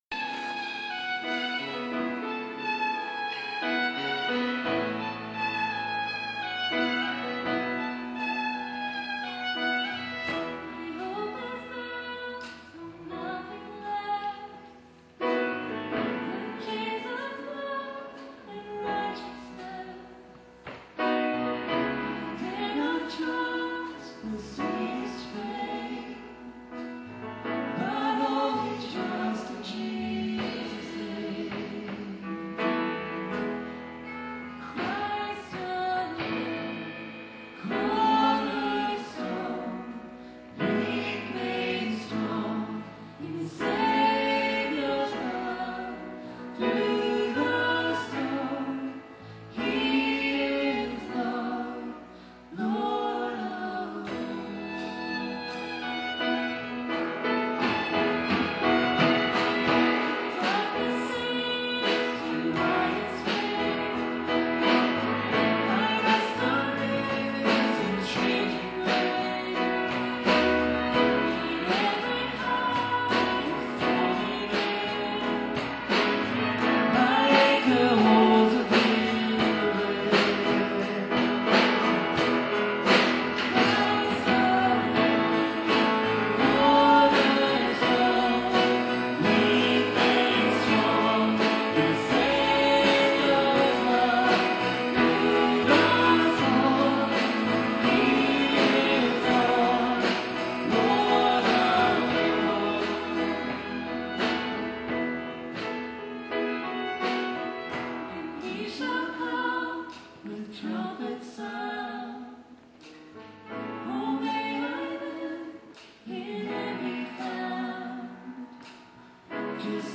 Cornerstone: a worship song originally recorded by Hill Song, but which I heard performed by a group called Dawn at a concert on April 30, 2017
This song may be known to those of you who are familiar with contemporary worship music.
:-) The song was originally recorded by the Christian band Hill Song, but I had the privilege of hearing it performed by a group of brilliant young musicians called Dawn. They were part of the program at an annual festival of praise I attended last night at my former minister's current church.